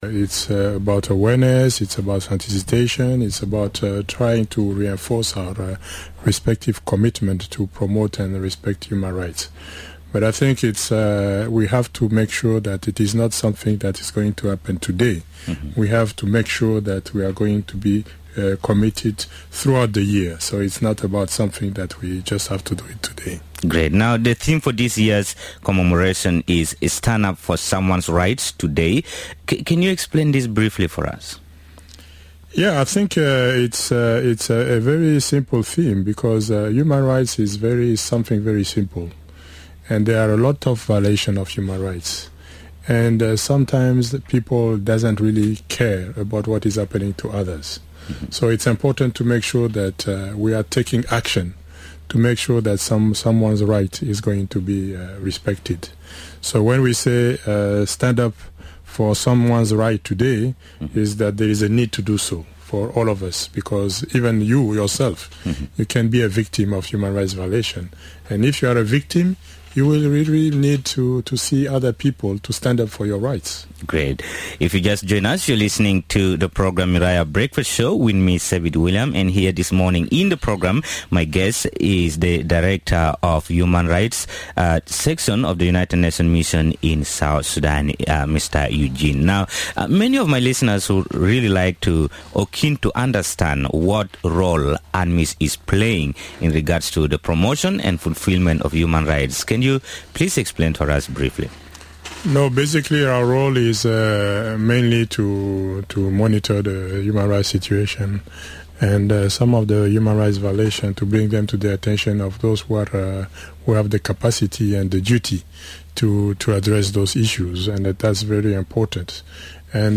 More in this interview